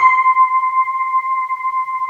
Index of /90_sSampleCDs/USB Soundscan vol.28 - Choir Acoustic & Synth [AKAI] 1CD/Partition D/08-SWEEPOR